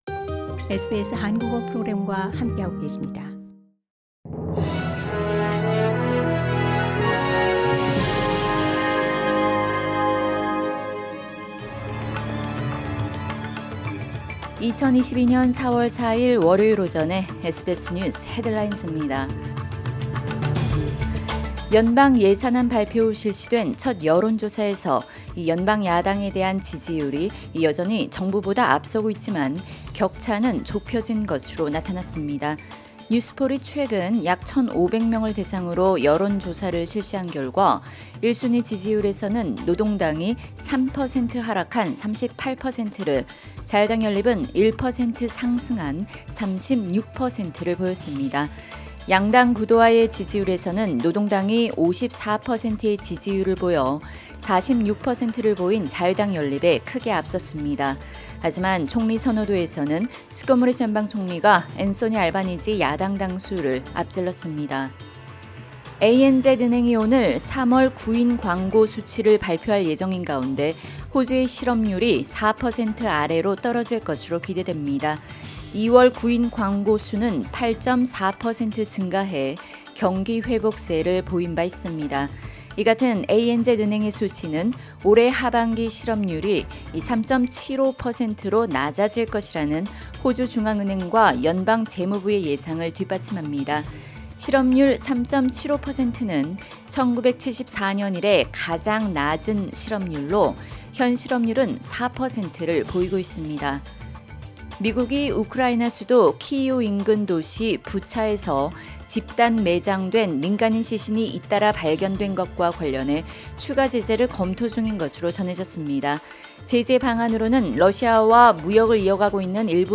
2022년 4월 4일 월요일 오전 SBS 뉴스 헤드라인즈입니다.